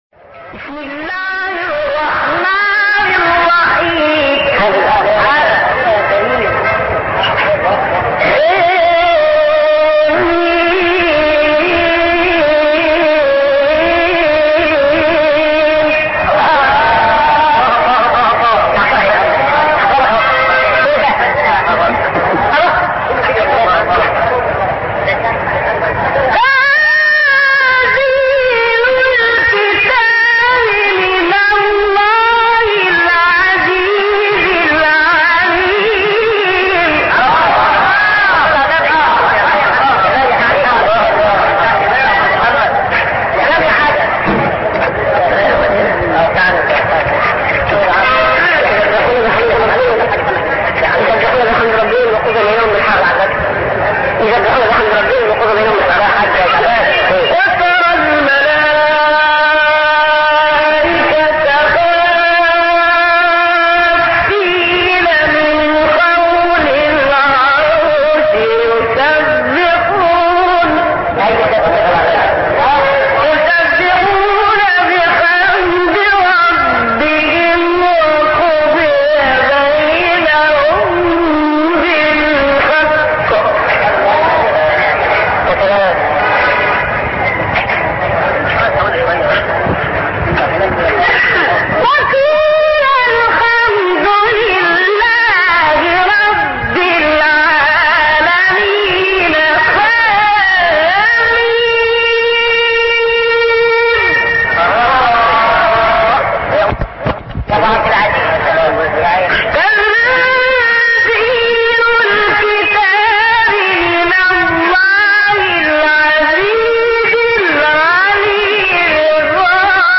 گروه فعالیت‌های قرآنی: مقاطع صوتی با صدای قاریان ممتاز کشور مصر را می‌شنوید.
تلاوتی ویژه از نوادر عبدالعزیز حصان